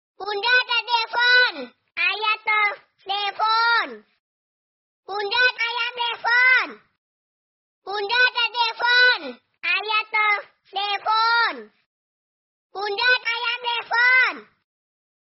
Kategori: Nada dering
Keterangan: Ini adalah nada dering suara bayi yang sangat lucu untuk Bunda.